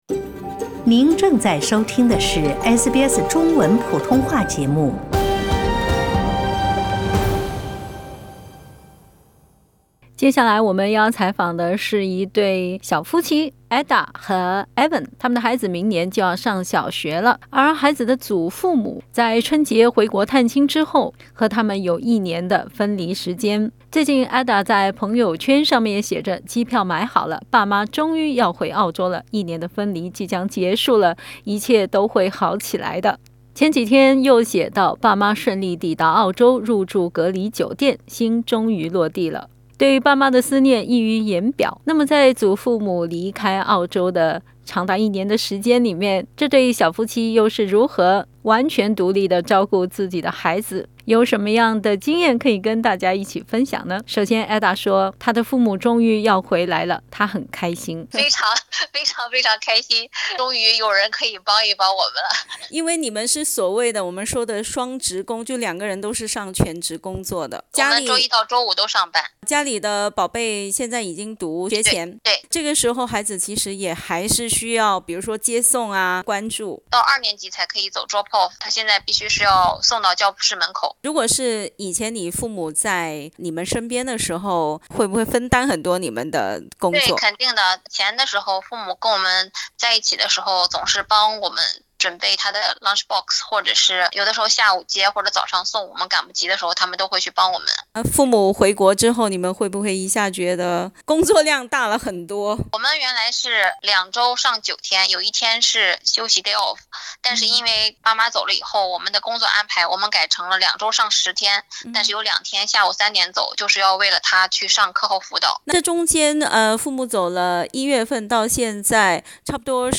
（点击上图收听录音采访） 澳大利亚人必须与他人保持至少 1.5米的社交距离，请查看您所在州或领地的最新社交限制措施 。